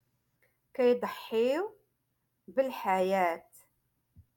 Moroccan Dialect- Rotation Five-Lesson sixty Three